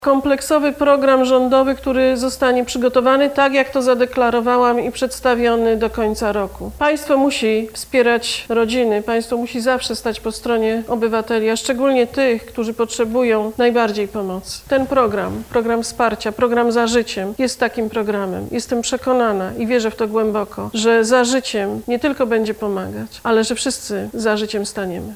– Cały program zostanie przedstawiony jeszcze w tym roku – zapowiedziała premier.